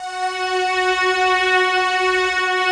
Index of /90_sSampleCDs/Optical Media International - Sonic Images Library/SI1_Swell String/SI1_Octaves